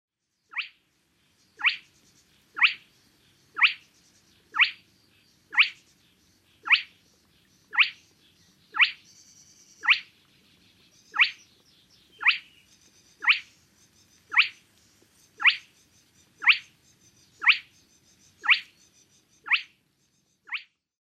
Luhtahuitti ääntelee öisin
Suomen öisin ääntelevät rantakanat ovat luhtakana ja luhtahuitti. Rytmit ovat helposti tunnistettavia ja yksinkertaisia.
Luhtahuitilla on tasaisen iskeviä piiskansivalluksia, jotka voivat jatkua tuntikausia lyhyin tauoin.